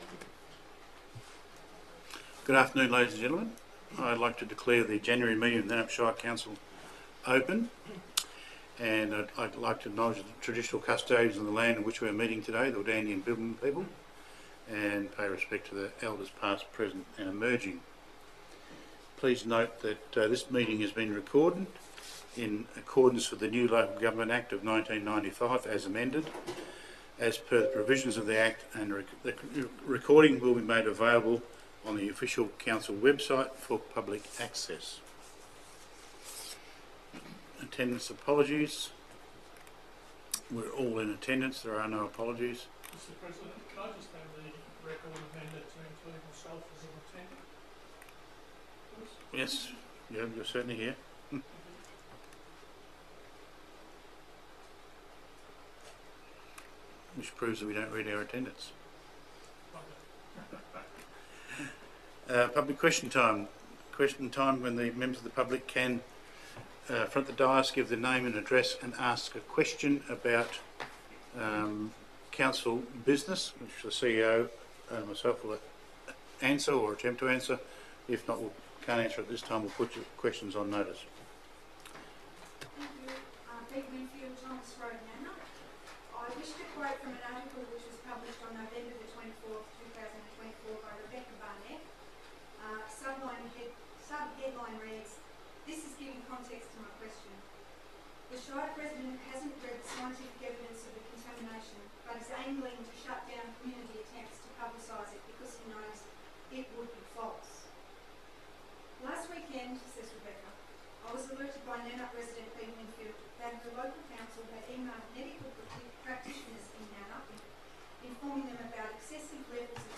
ORDINARY COUNCIL MEETING » Shire of Nannup